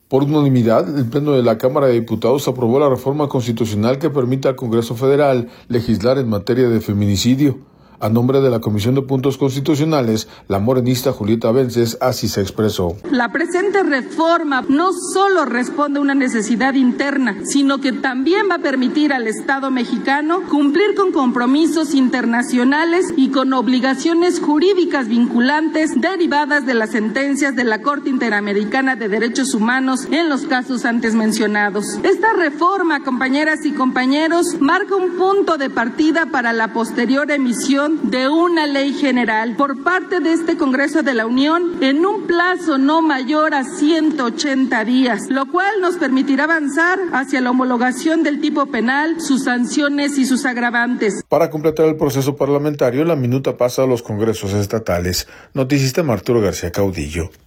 audio Por unanimidad, el Pleno de la Cámara de Diputados aprobó la reforma constitucional que permite al Congreso Federal legislar en materia de feminicidio. A nombre de la Comisión de Puntos Constitucionales, la morenista Julieta Vences, así se expresó.